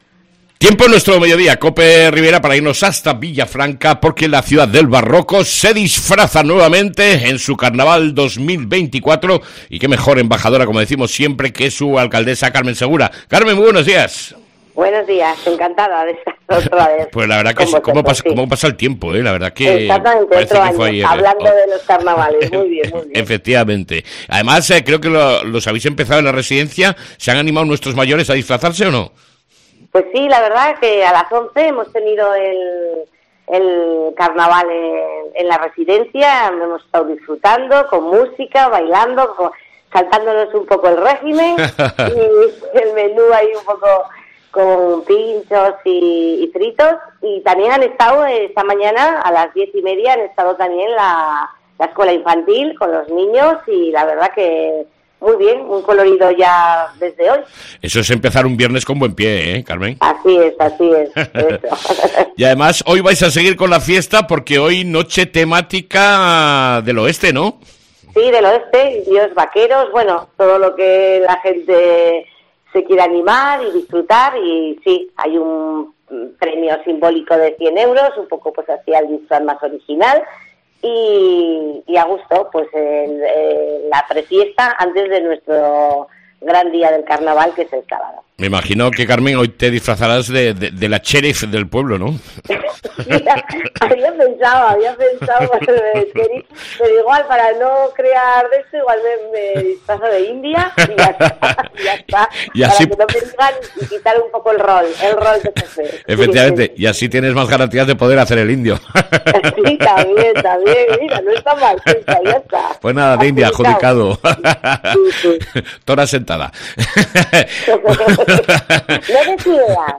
ENTREVISTA CON LA ALCALDESA DE VILLAFRANCA, Mª CARMEN SEGURA